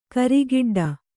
♪ karigiḍḍa